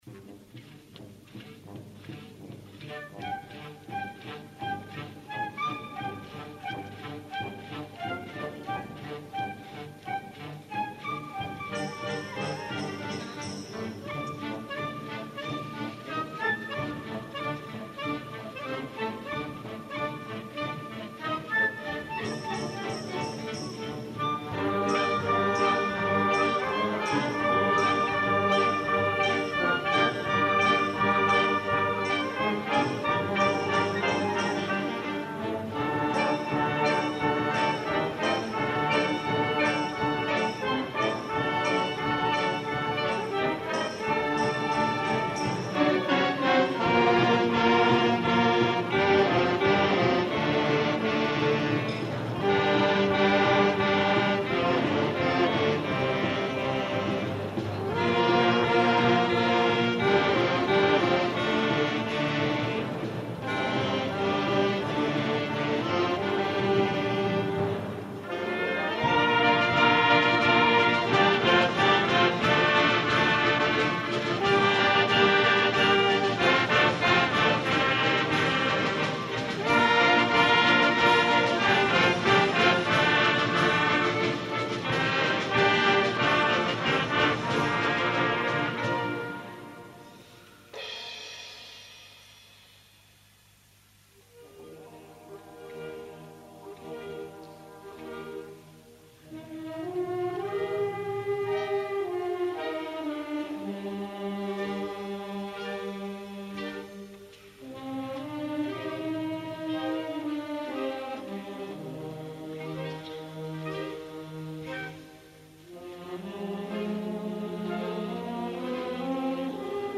Concert sa fira 1988. Esglesia parroquial de Porreres Nostra Senyora de la Consolació.